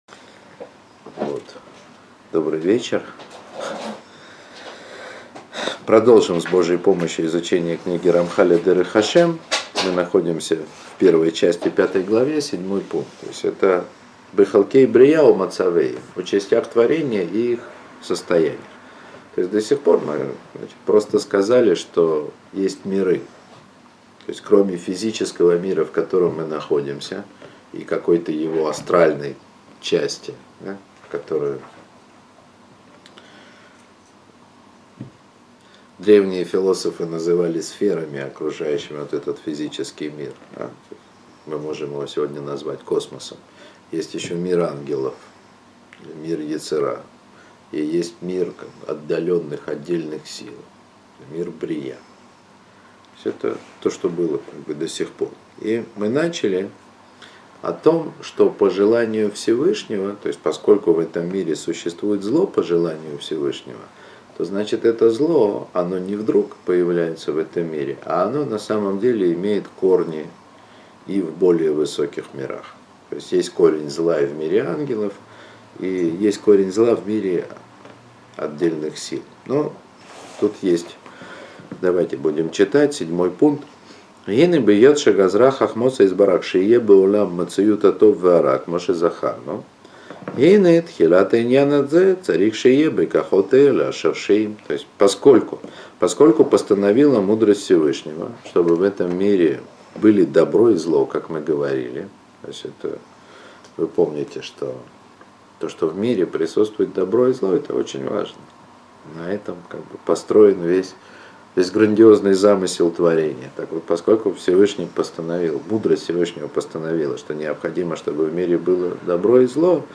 Уроки по книге Рамхаля.